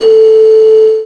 55bw-flt16-a4.aif